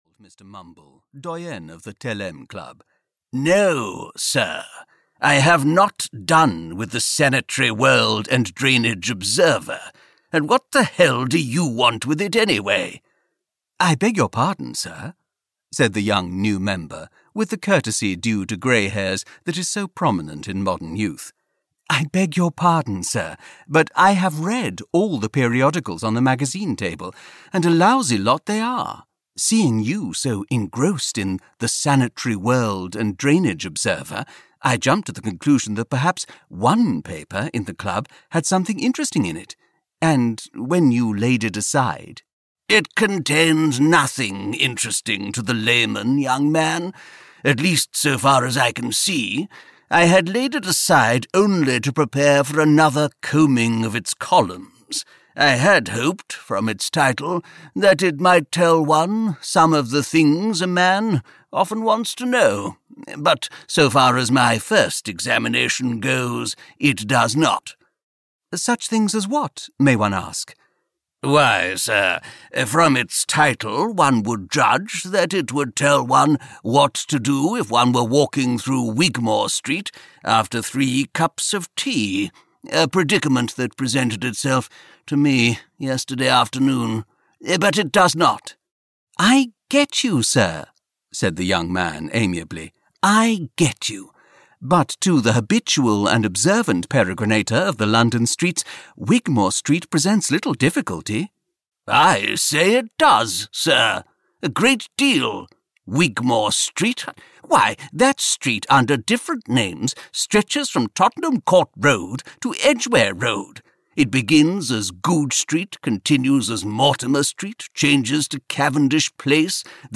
Audio knihaFor Your Convenience - A CLASSIC 1930'S GUIDE TO LONDON LOOS (EN)
Ukázka z knihy